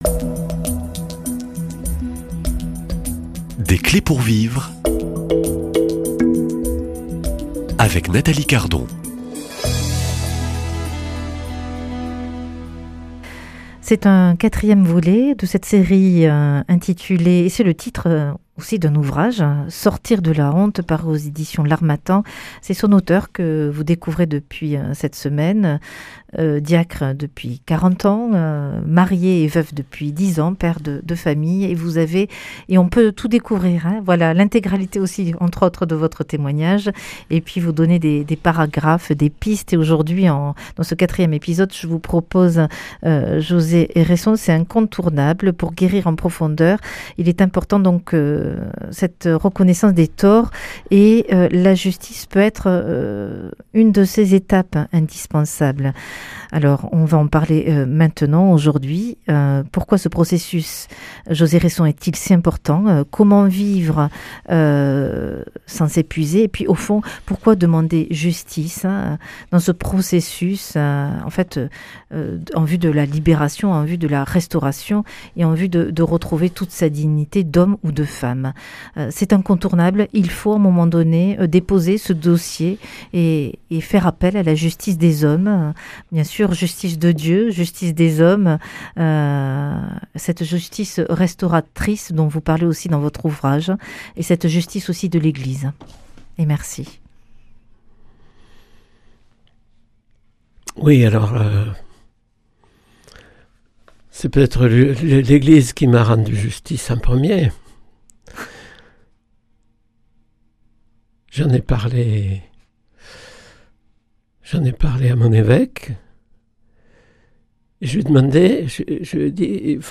Dans cet entretien, nous allons parler du besoin de justice et des difficultés que ce chemin comporte. Pourquoi ce processus est-il si important ?